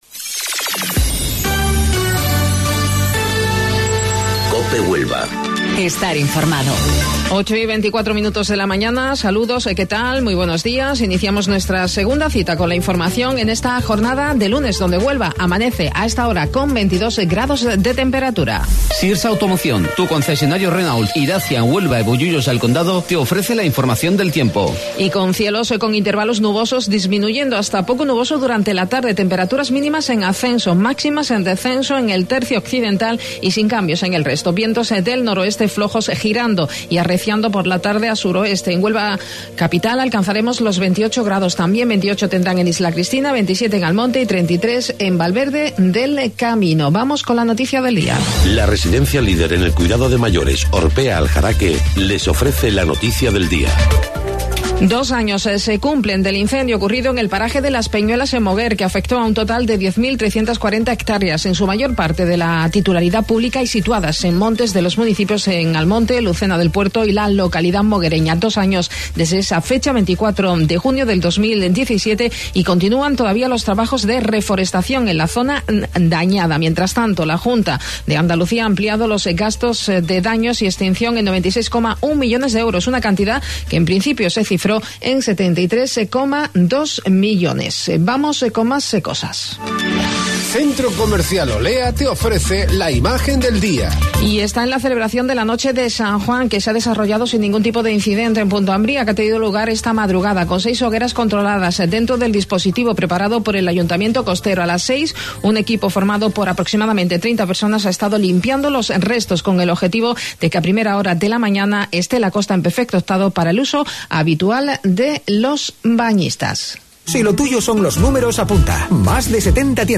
AUDIO: Informativo Local 08:25 del 24 de Junio